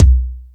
Lotsa Kicks(36).wav